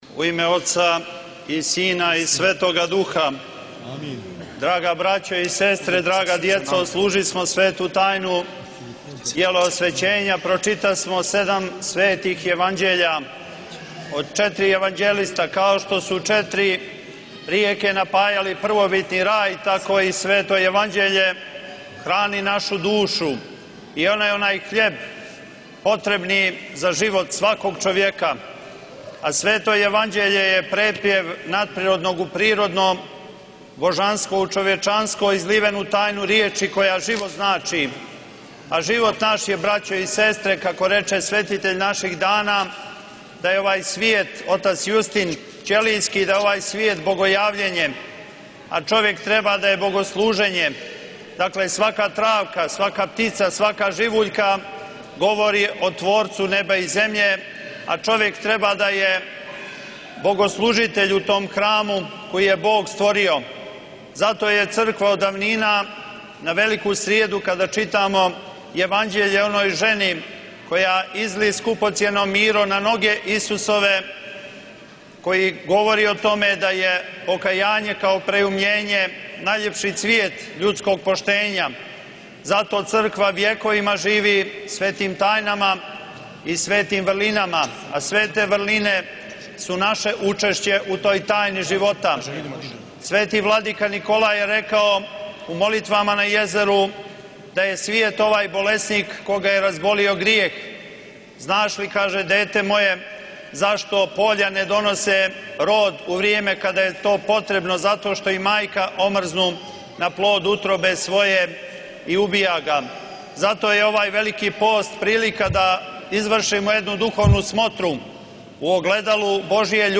Besjeda
na službi Svete tajne jeleosvećenja u Sabornom hramu u Podgorici